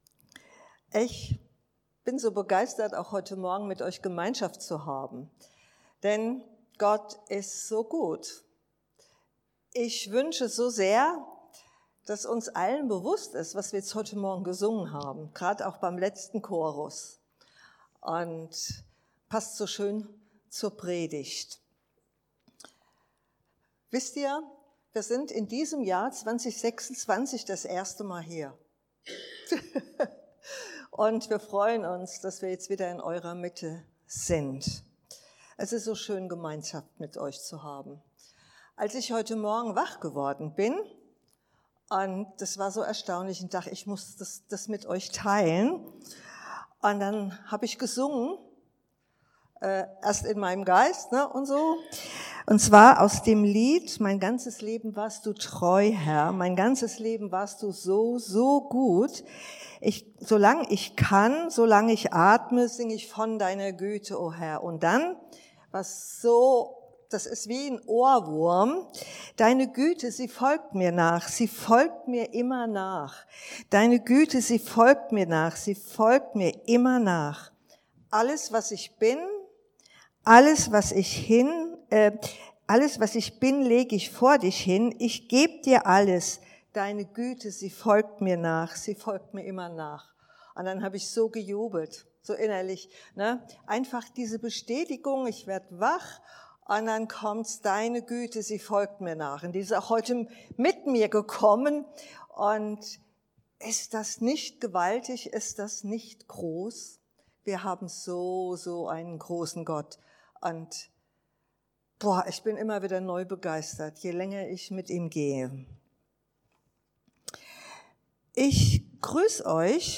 Hebr.13,8 Dienstart: Predigt Ich mache alles neu!